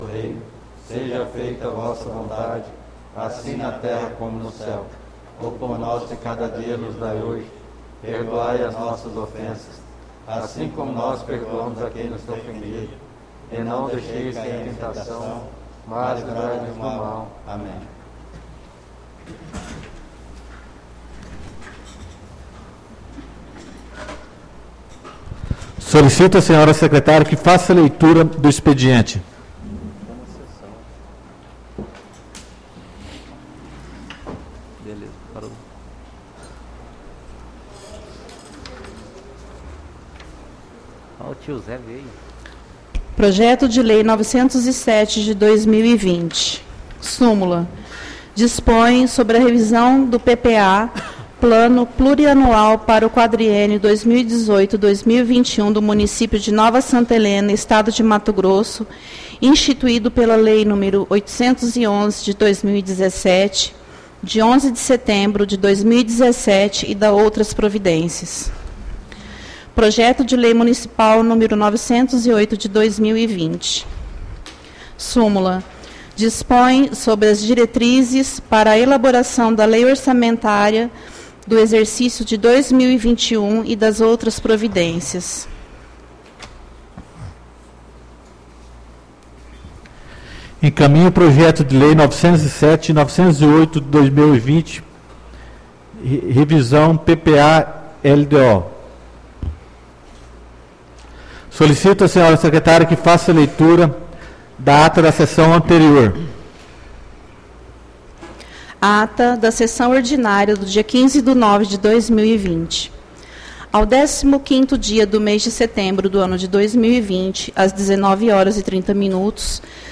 Sessão Ordinária do dia 06/10/2020